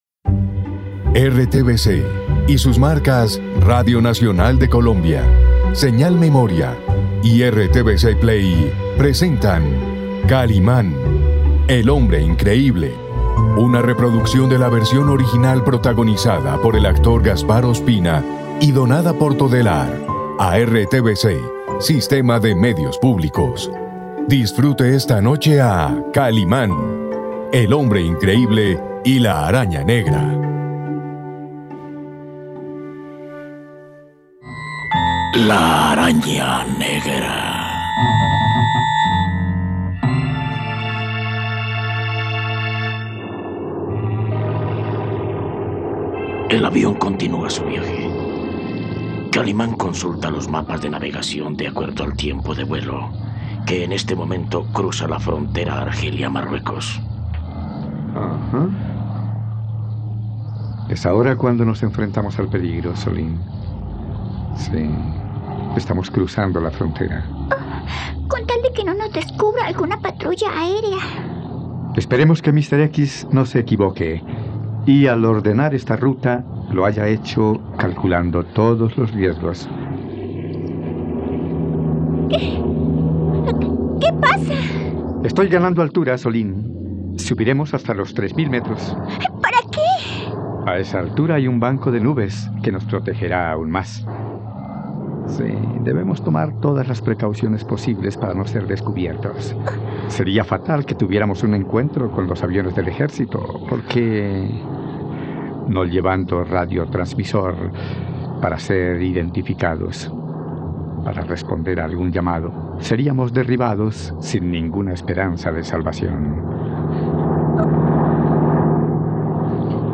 Podcast narrativo de ficción.